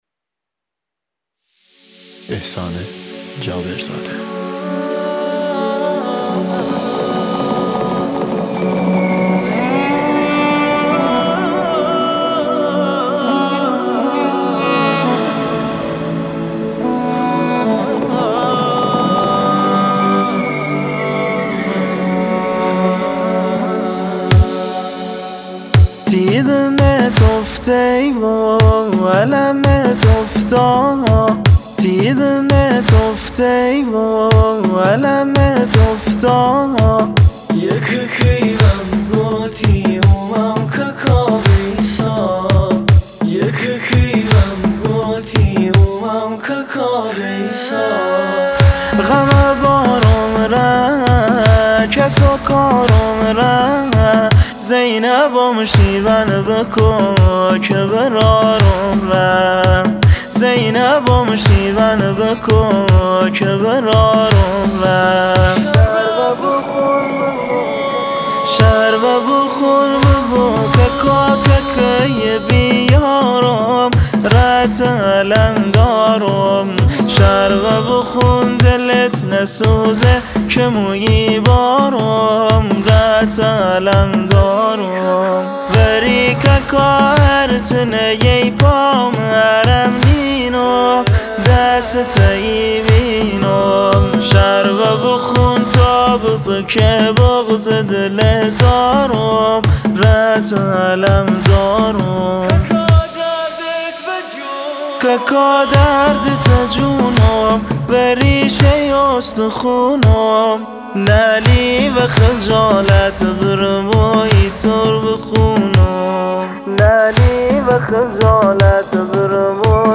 مداحی لری